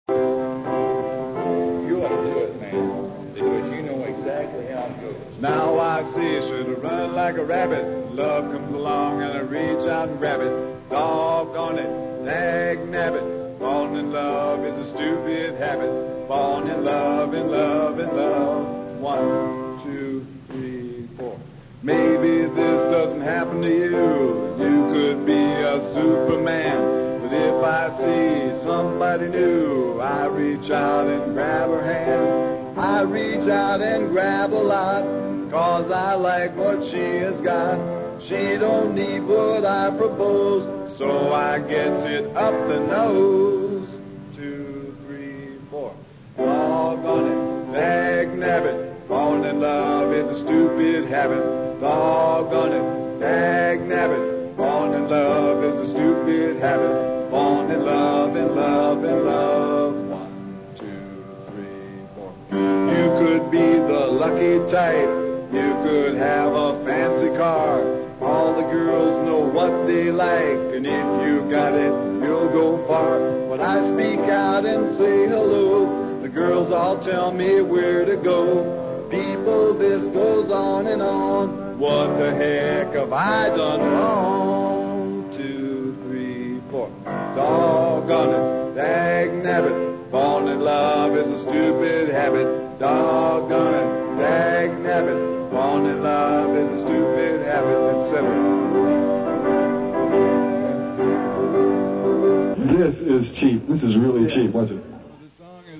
vocals and piano